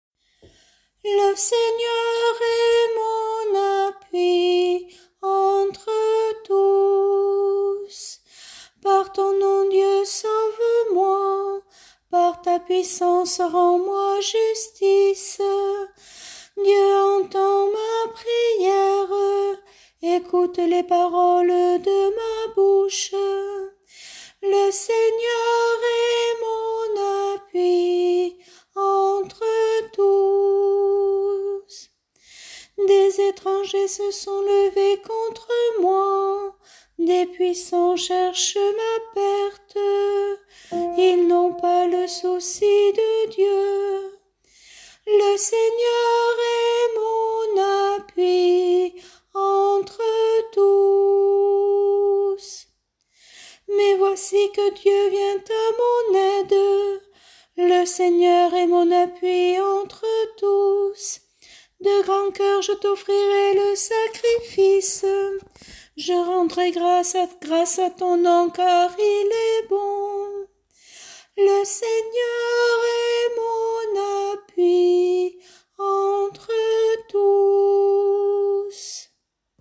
Chorale psaumes année B – Paroisse Aucamville Saint-Loup-Cammas